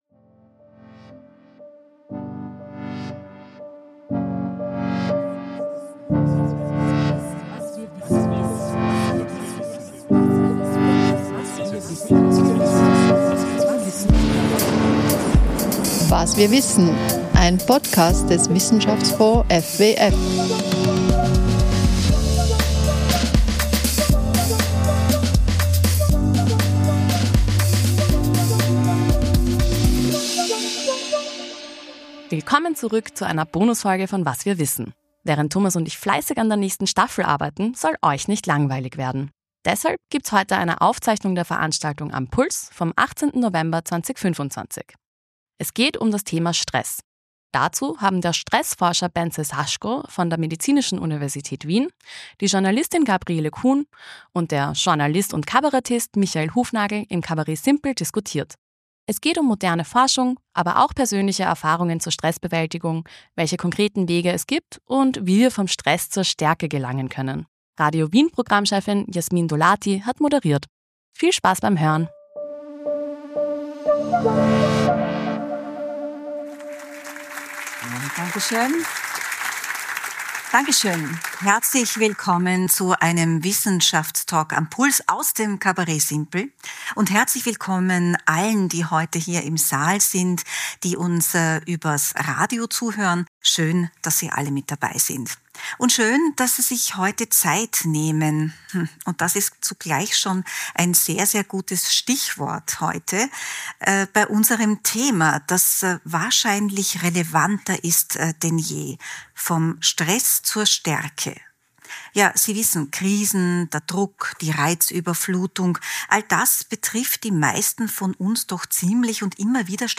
Die Wissenschaftstalks “Am Puls“ sind eine Veranstaltung des österreichischen Wissenschaftsfonds FWF in Kooperation mit dem ORF-Radio Wien.